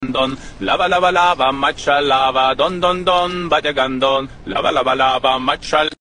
lava lava matsh alava Meme Sound Effect
This sound is perfect for adding humor, surprise, or dramatic timing to your content.